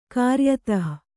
♪ kāryatah